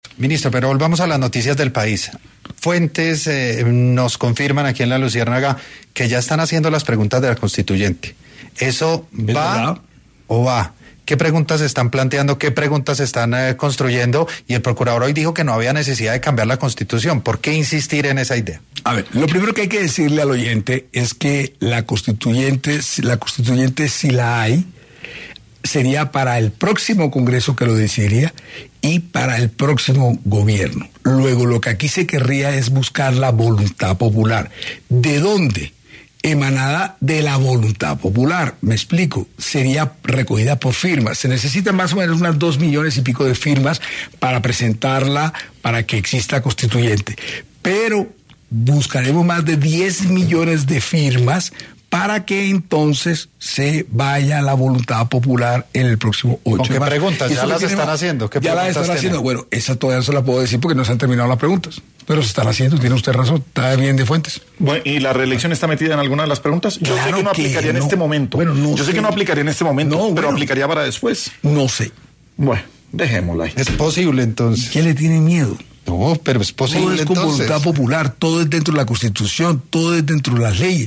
En Sin Anestesia de Caracol Radio, estuvo el Ministro del Interior, Armando Benedetti, y habló sobre la posibilidad de una nueva constituyente